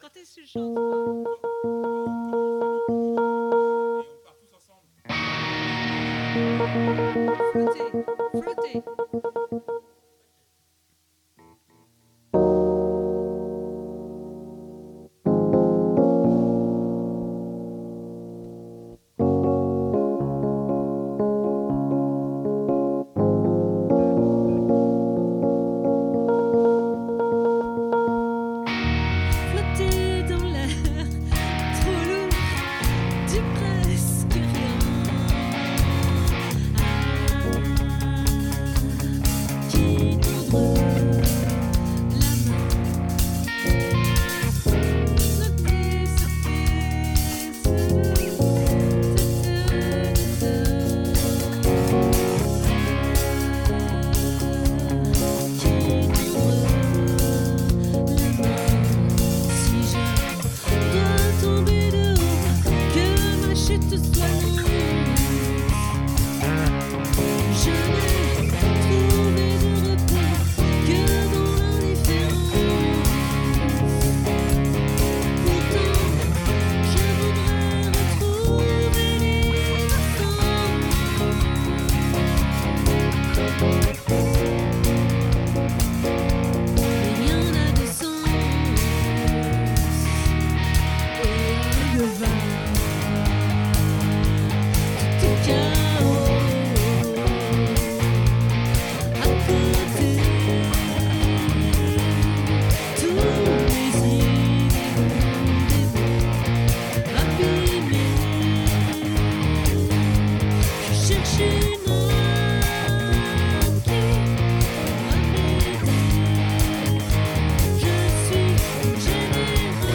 🏠 Accueil Repetitions Records_2025_12_08